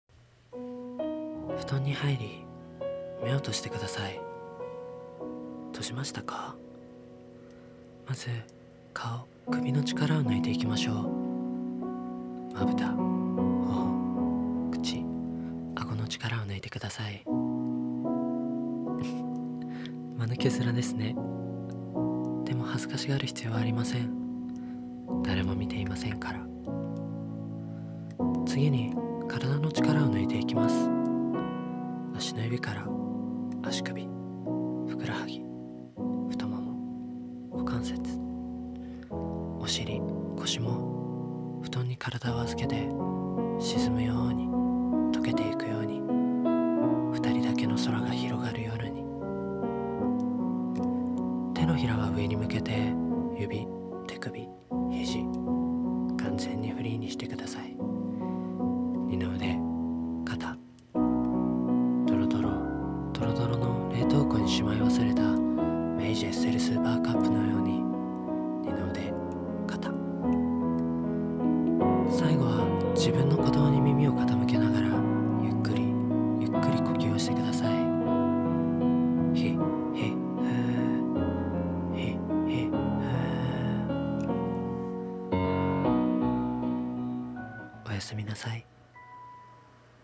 私的睡眠導入音声［台本］